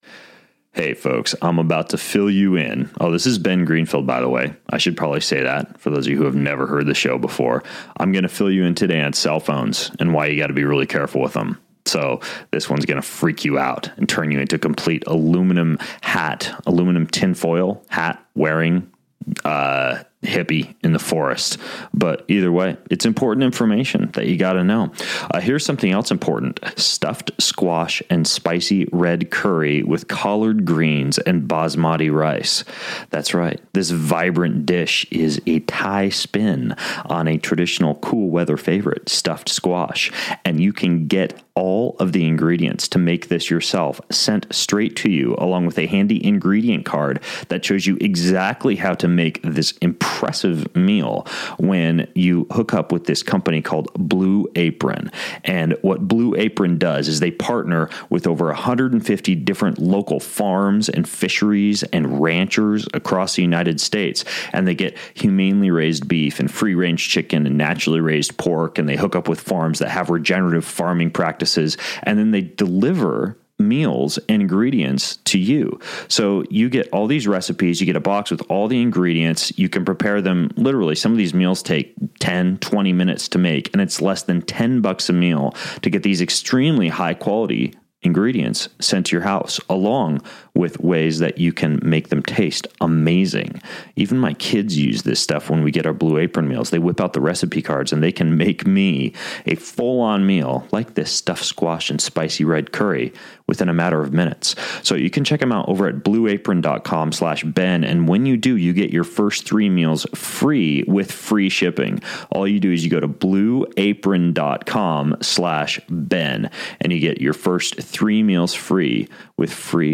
My guest on today’s podcast is an internationally recognized and influential expert in shielding electronic emissions and Electromagnetic Radiation (EMF)